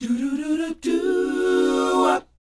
DOWOP G#4A.wav